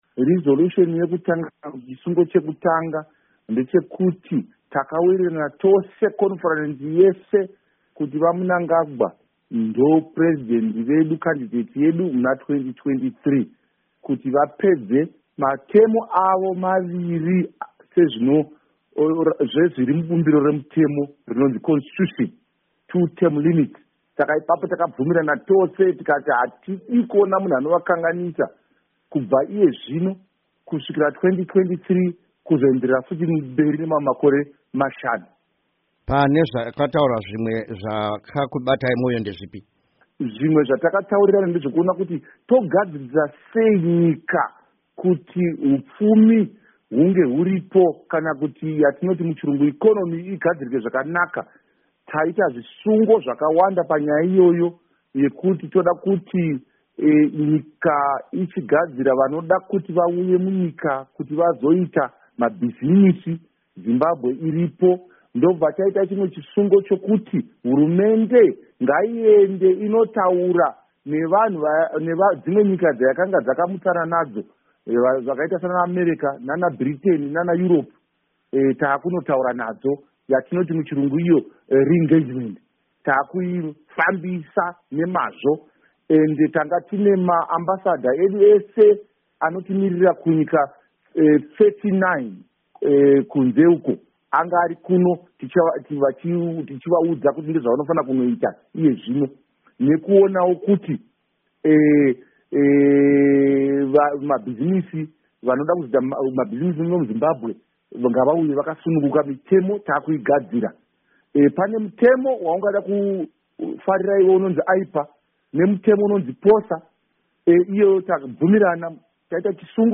Hurukuro naVaKindness Paradza